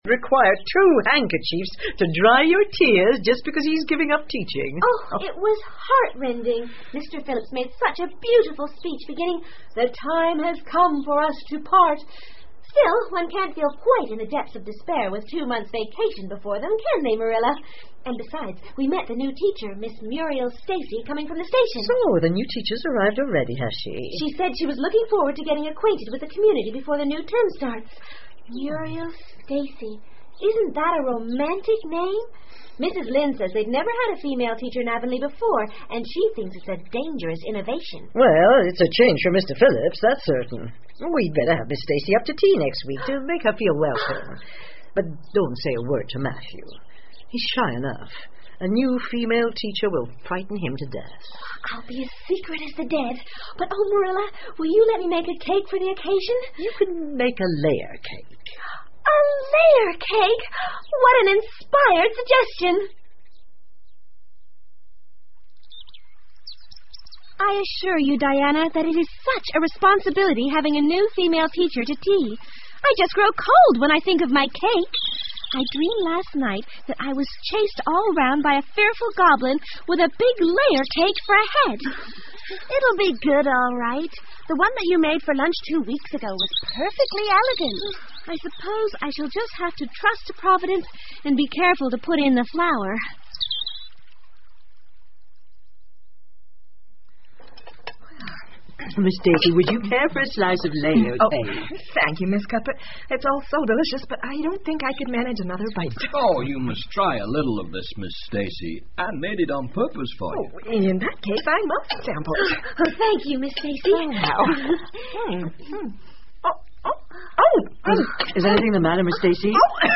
绿山墙的安妮 Anne of Green Gables 儿童广播剧 15 听力文件下载—在线英语听力室